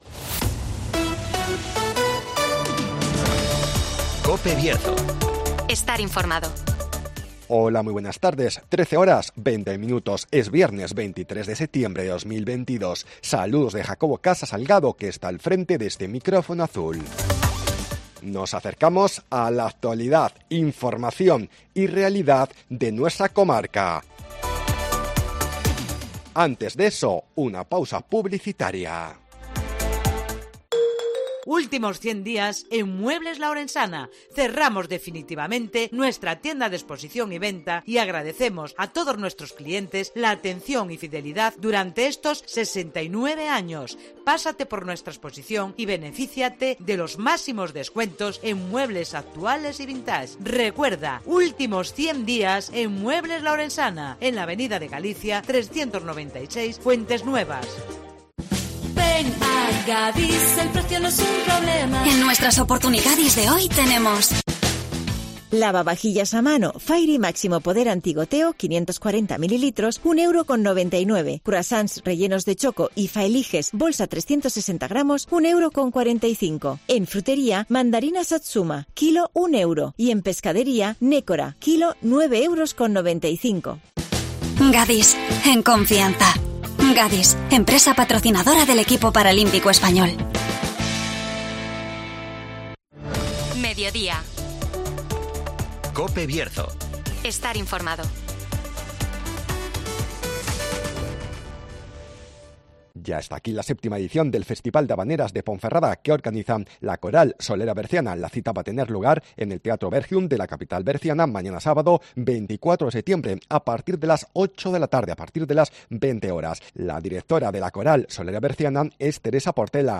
Ya está aquí la séptima edición Festival de Habaneras de Ponferrada (Entevista